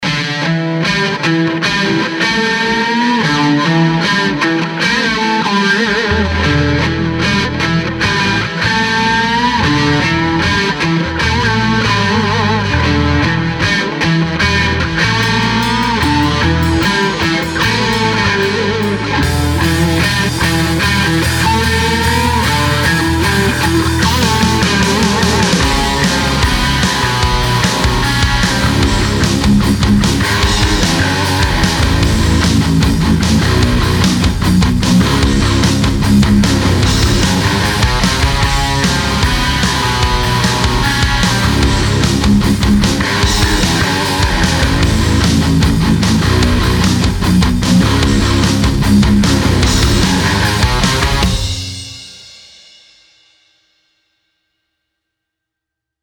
�������: Edwards LP Custom, Schecter BJ 7, ����� ������, ���� �����, � Randall Diavlo RD5, ��� Blackstar 4x8 Blackbird, ������ ...